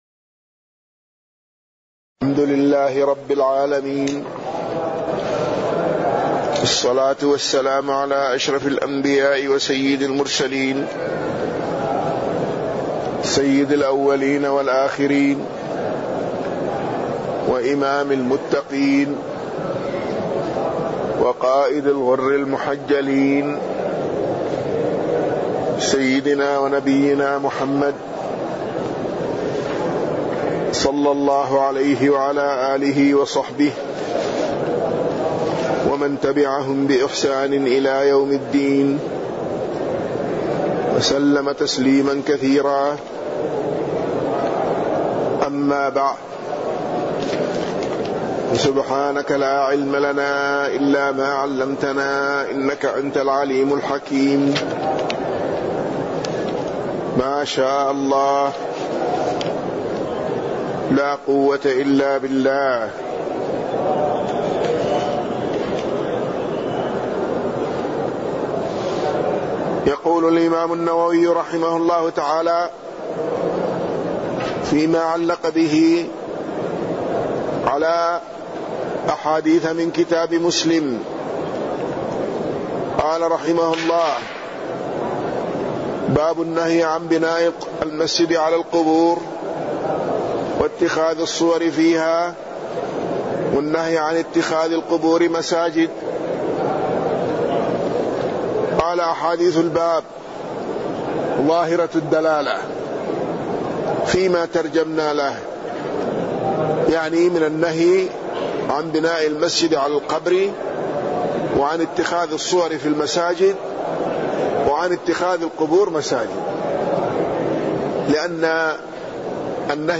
تاريخ النشر ٢٨ ربيع الثاني ١٤٢٩ هـ المكان: المسجد النبوي الشيخ